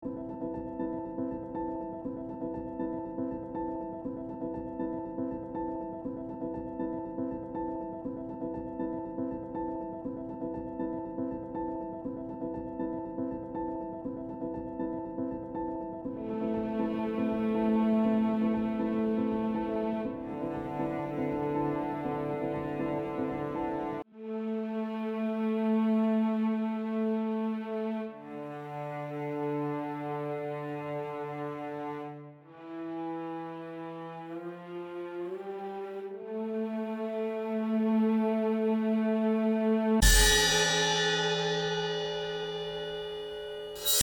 mp3,1032k] Классика